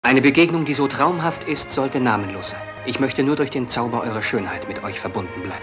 Hörprobe des deutschen Synchronschauspielers
Vergeltung-Glemnitz02.mp3